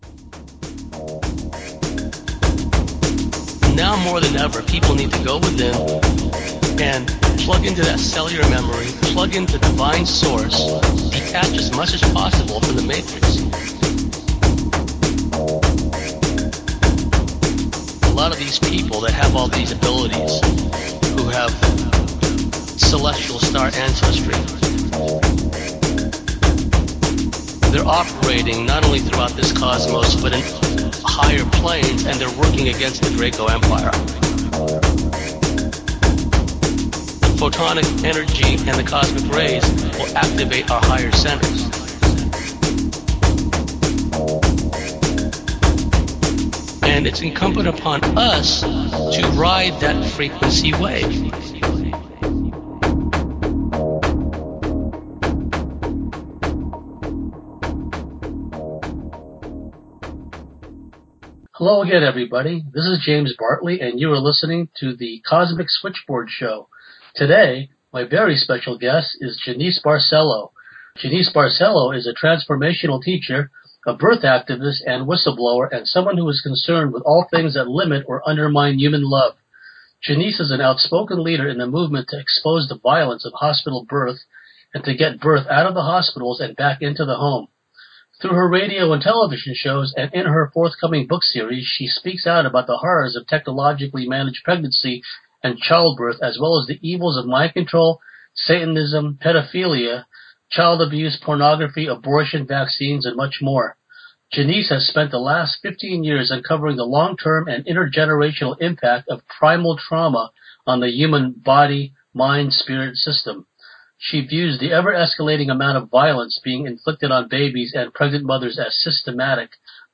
Interview about Birth Trauma and Circumcision